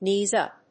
アクセントknées‐ùp